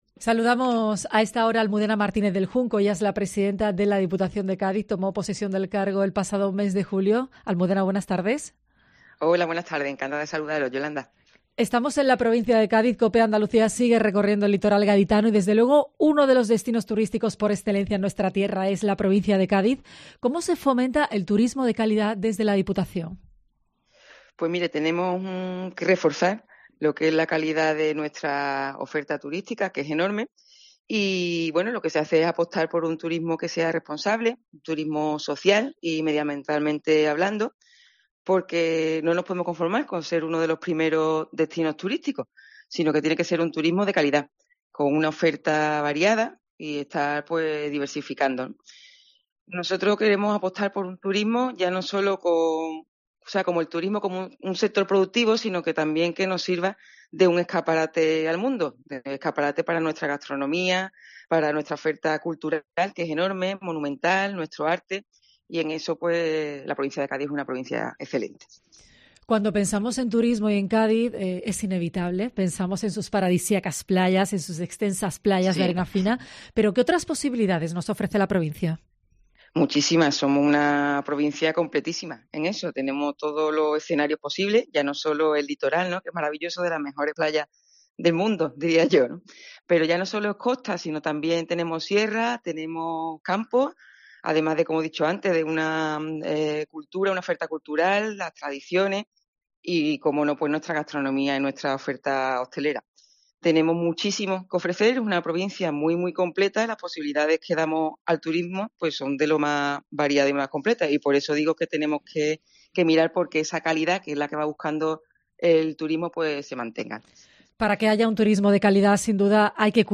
Aquí nos recibe Almudena Martínez del Junco, elegida presidenta de la Diputación Provincial de Cádiz el pasado 12 de julio gracias al acuerdo alcanzado entre PP y La Línea 100x100.